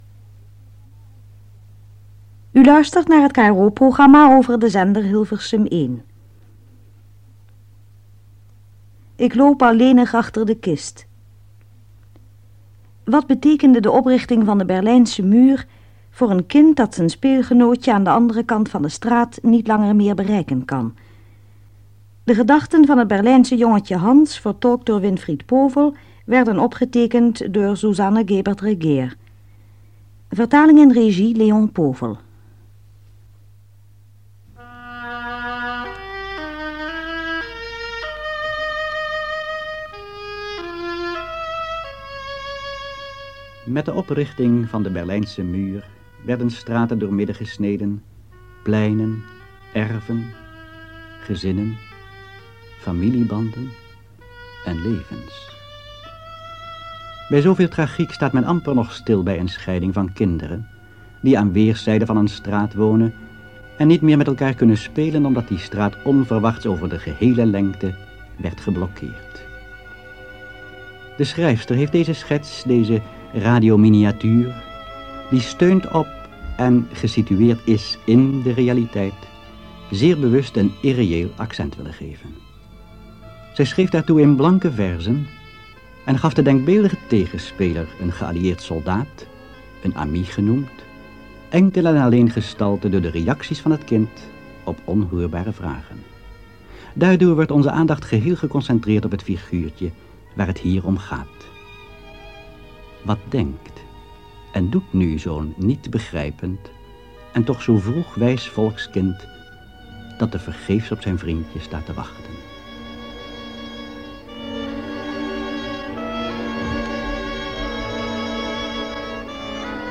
Monoloog (KRO) 1 deel
Dit 1-delige hoorspel duurt ongeveer 14 minuten.